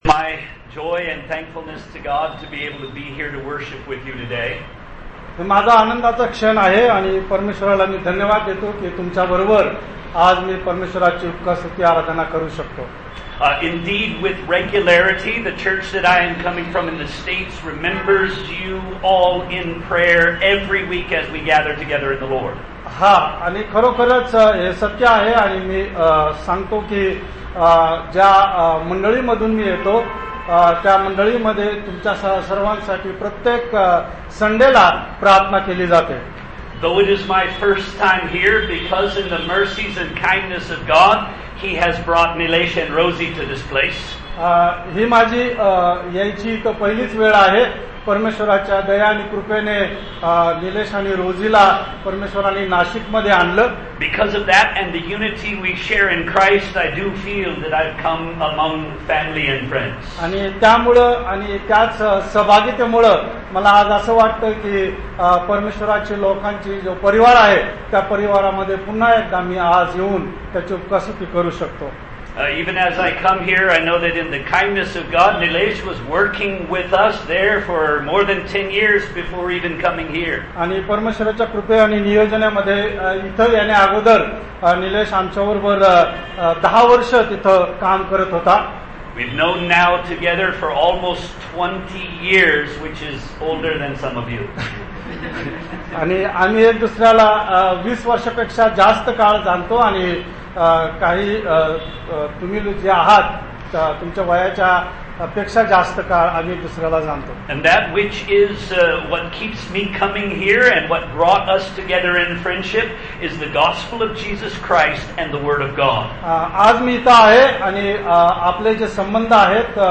Passage: Acts 4:23-31 Service Type: Sunday Service Topics